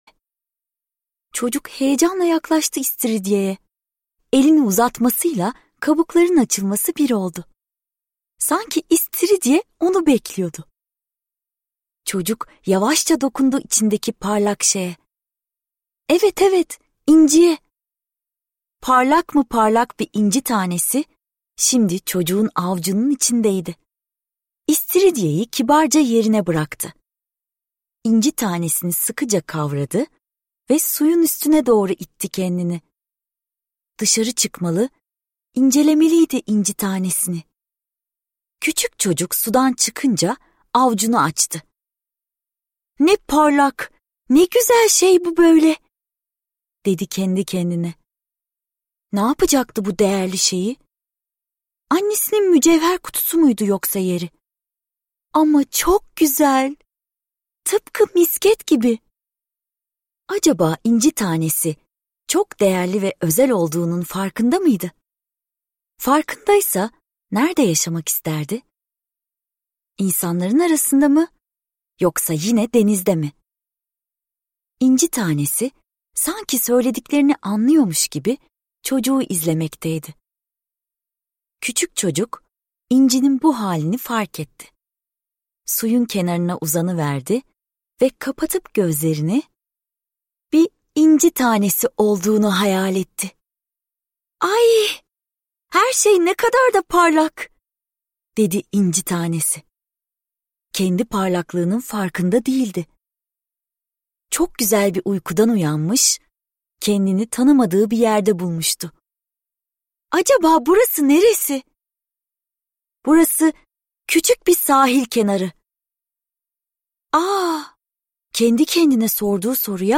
İnci Tanesi - Seslenen Kitap